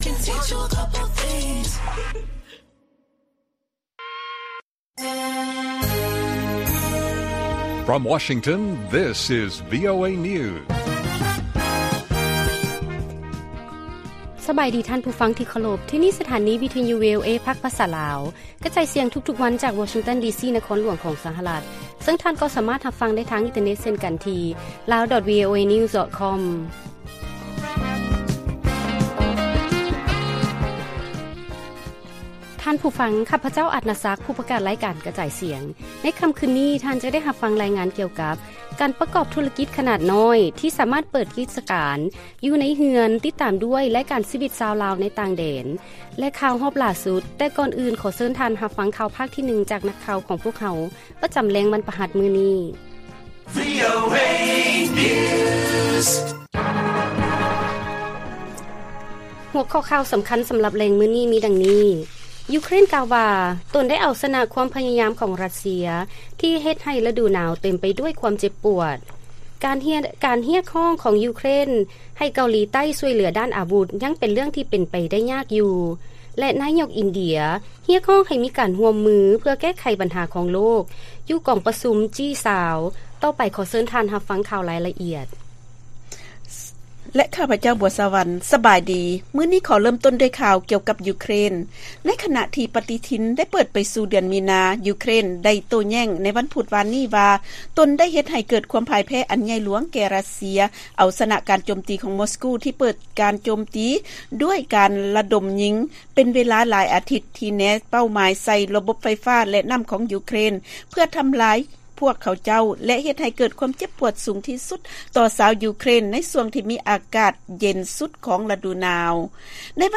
ລາຍການກະຈາຍສຽງຂອງວີໂອເອ ລາວ: ຢູເຄຣນກ່າວວ່າ ຕົນໄດ້ເອົາຊະນະຄວາມພະຍາຍາມຂອງ ຣັດເຊຍທີ່ເຮັດໃຫ້ລະດູໜາວເຕັມໄປດ້ວຍຄວາມເຈັບປວດ